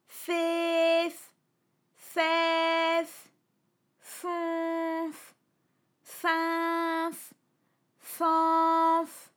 ALYS-DB-001-FRA - First, previously private, UTAU French vocal library of ALYS
fehfaifonfinfanf.wav